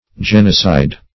genocide \gen"o*cide\ n.